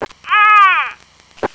death4.wav